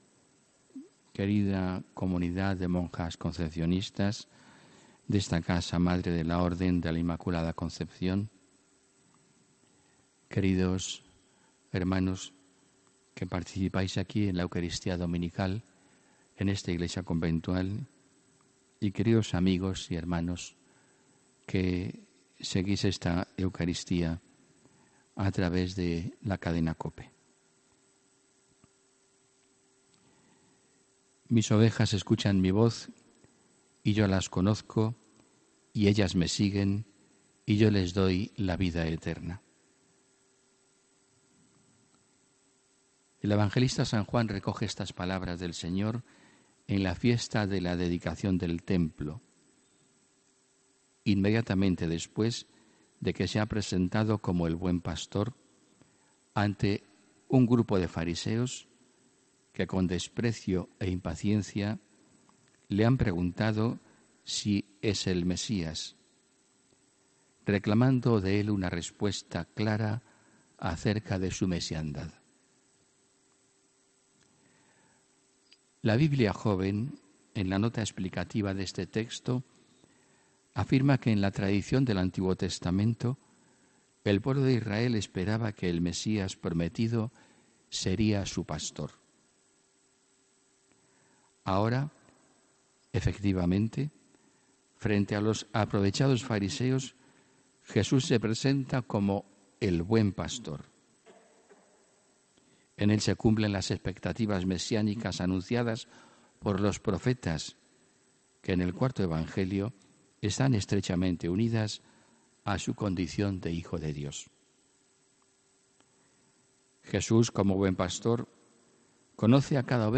HOMILÍA 12 MAYO 2019